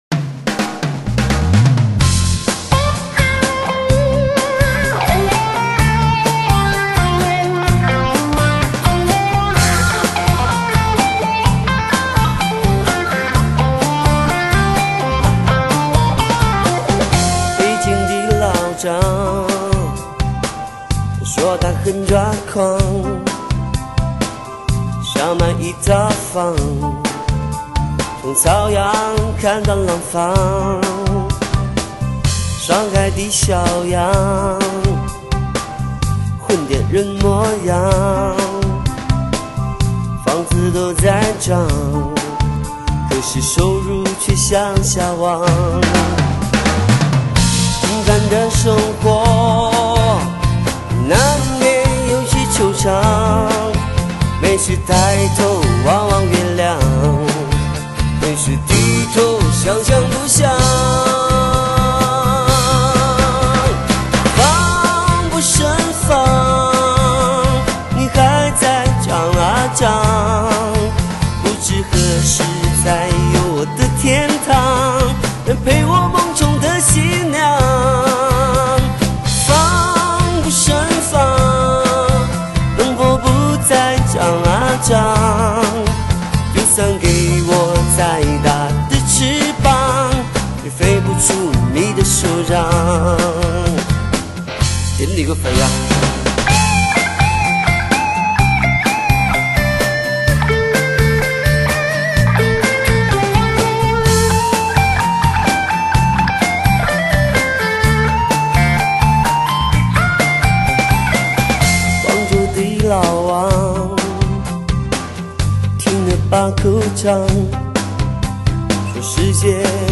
风格流派：pop